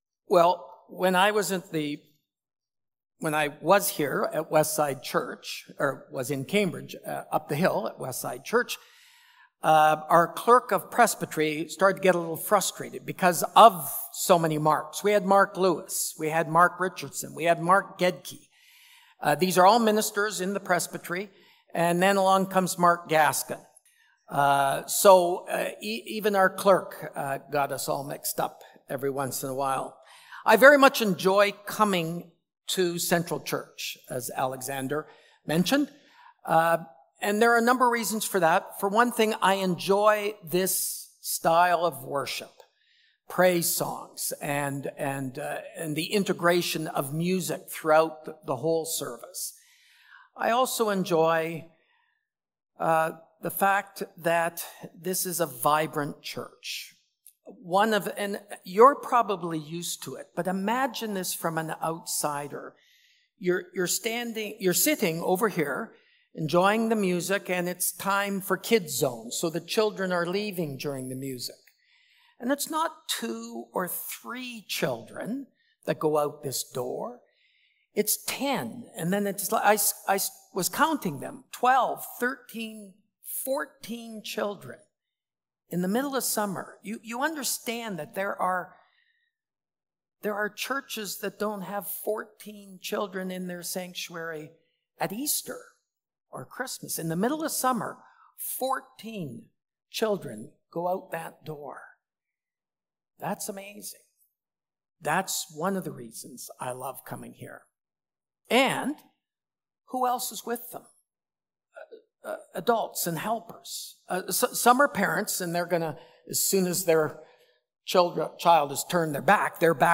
July-28-Sermon.mp3